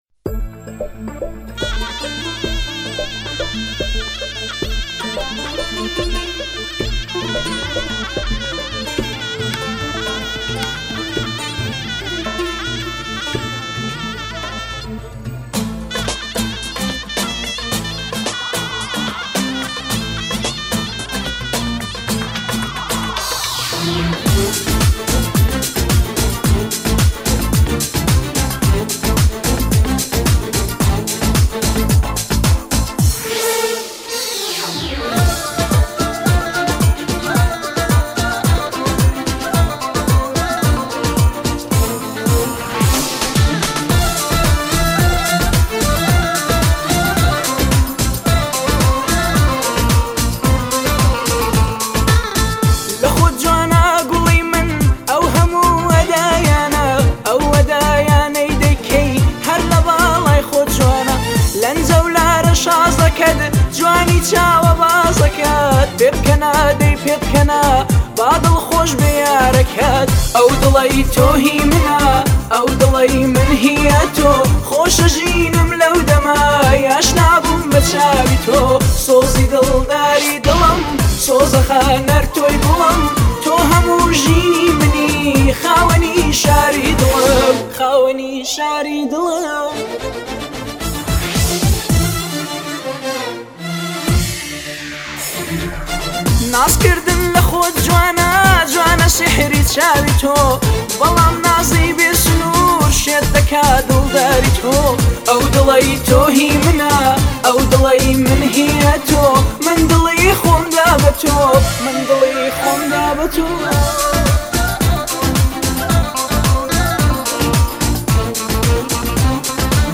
آهنگ کوردی